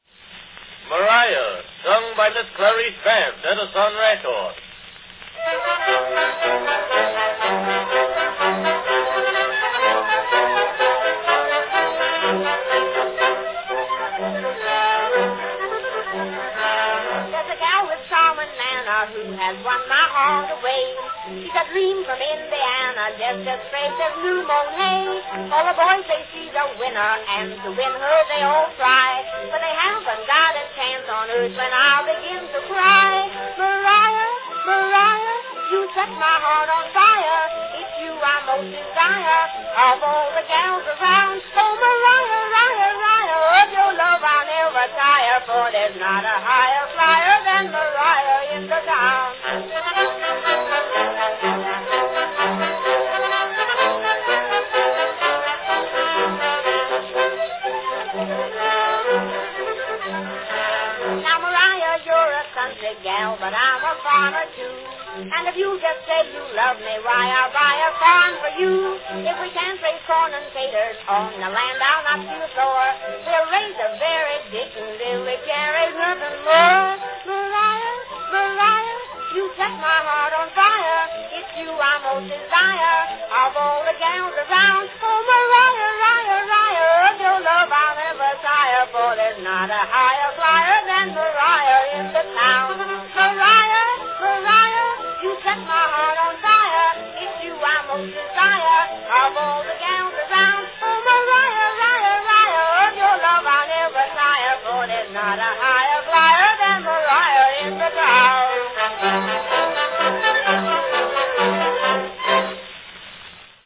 Category Contralto
The Record is made with orchestra accompaniment.